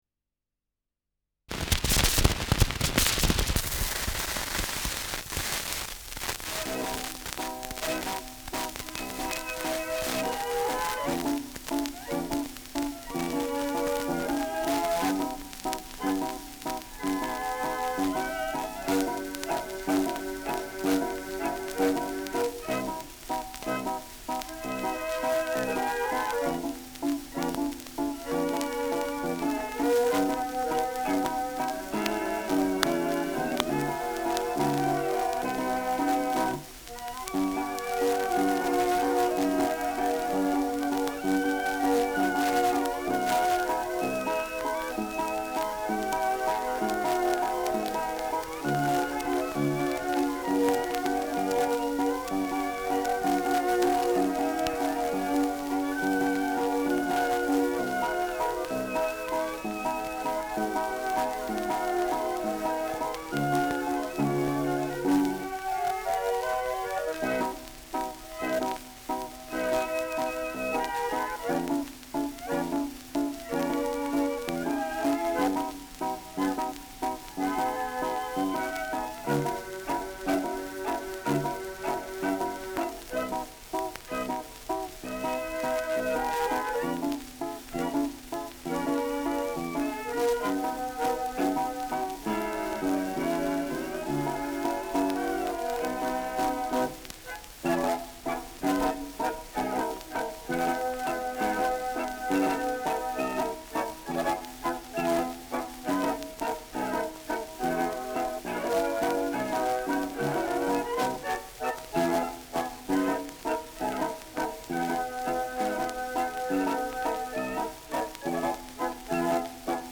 Schellackplatte
ausgeprägtes Rauschen
Original Lanner-Quartett (Interpretation)